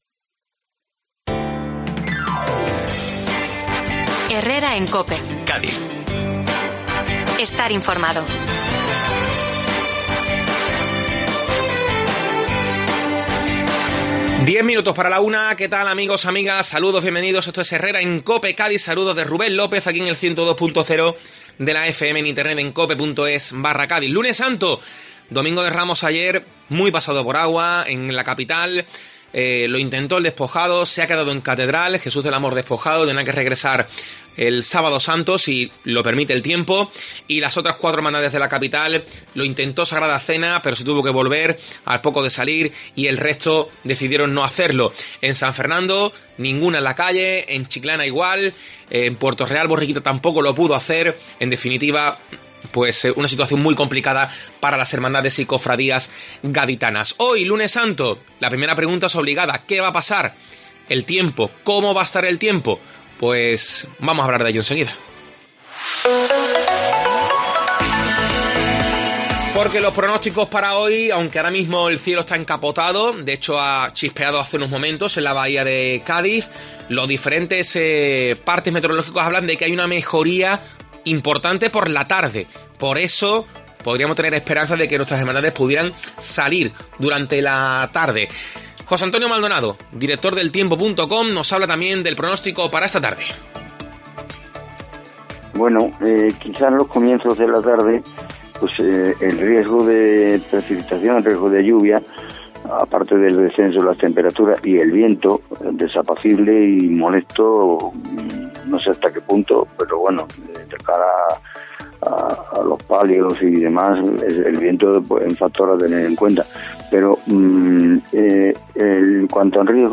José Antonio Maldonado, prestigioso meterólogo, habla del tiempo en COPE especificando que hay mejoría del tiempo por la tarde pero que la lluvia puede volver a aparecer a última hora del día.